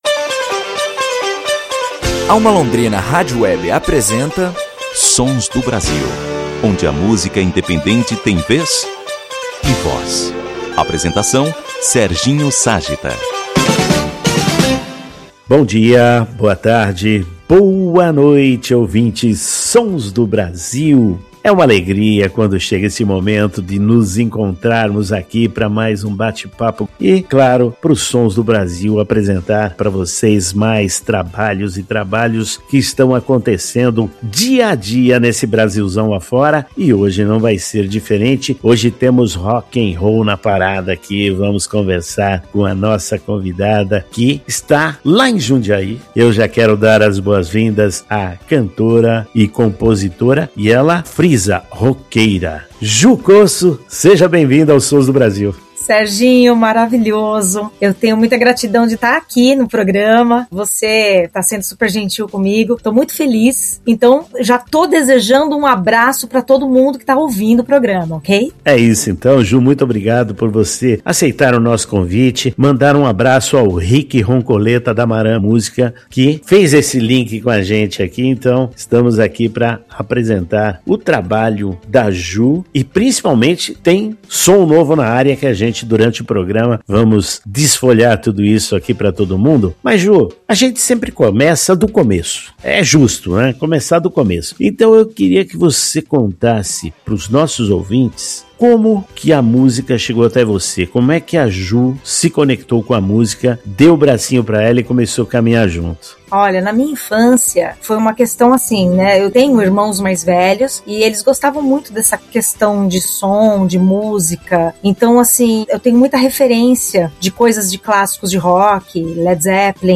No SONS DO BRASIL 570 recebemos para o nosso bate-papo a cantora